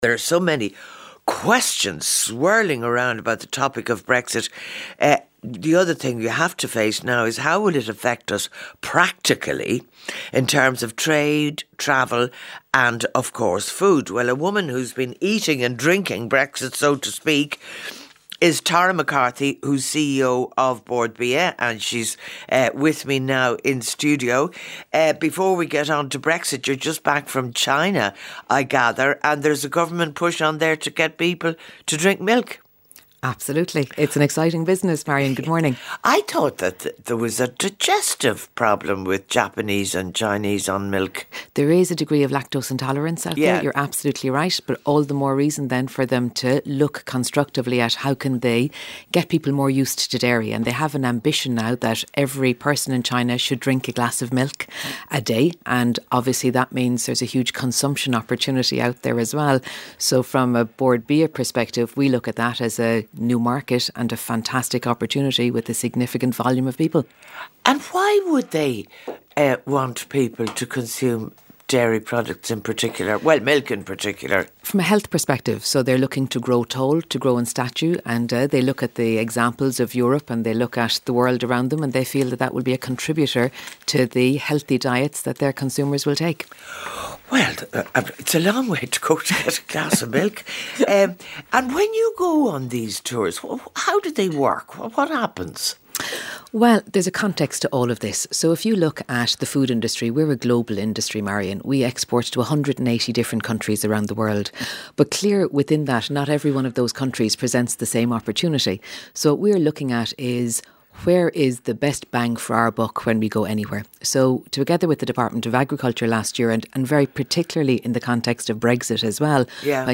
The whole interesting interview is 39 minutes 40 seconds long, but only the first half is about the Brexit related issues, the majority of the remainder on other issues connected with Irish food production for export.